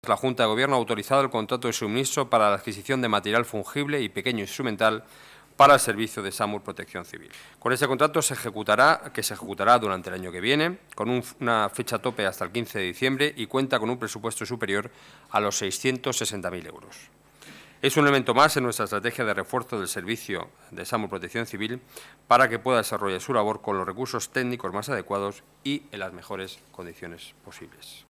Nueva ventana:Declaraciones portavoz Gobierno municipal, Enrique Núñez: inversión para SAMUR